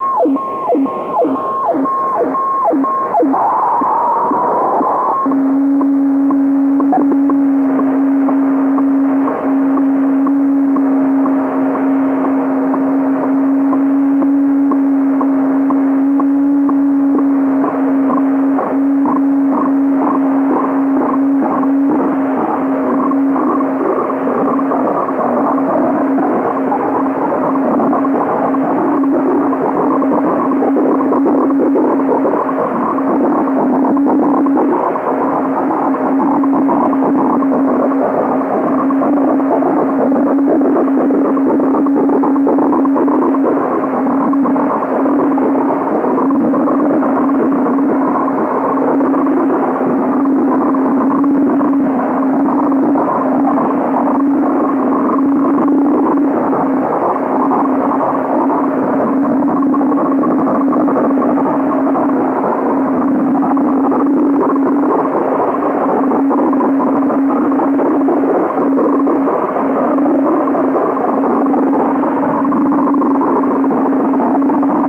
AM/FM/SW radio sounds » sw sounds 3
描述：Different data transmission sounds in a shortwave radio band. Recorded from an old Sony FM/MW/LW/SW radio reciever into a 4thgen iPod touch around Feb 2015.
标签： data shortwave radio vlf communication transmission electronic noise
声道立体声